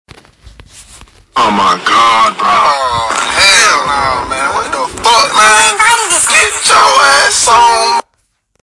Oh My God Meme Crispy Loud Botão de Som